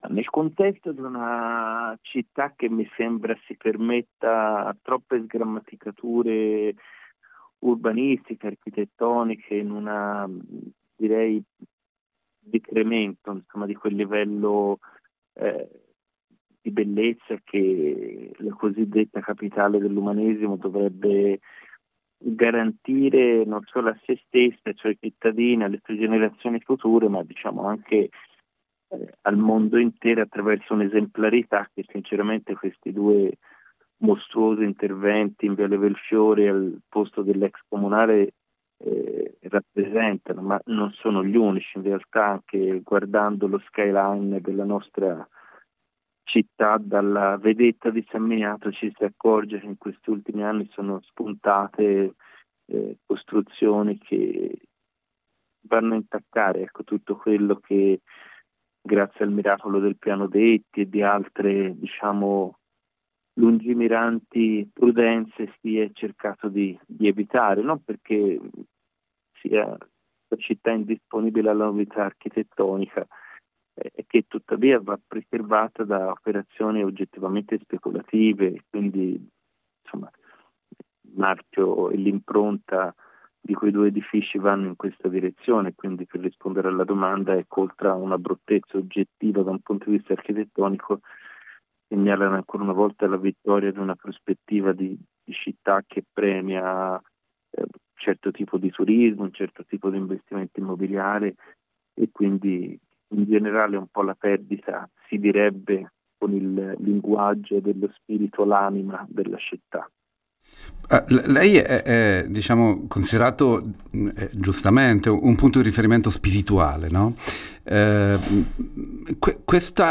Lo abbiamo intervistato